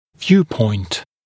[‘vjuːpɔɪnt][‘вйу:пойнт]точка зрения